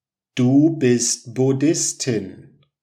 ดู บิส(ท) บุด-ดิส-ทิน